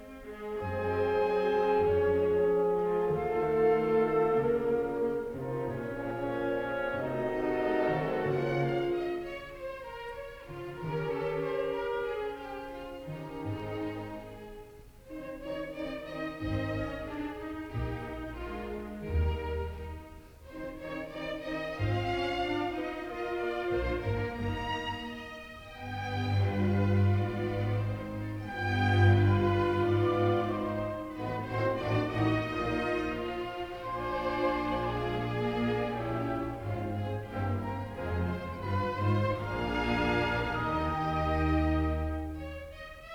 Nicht schnell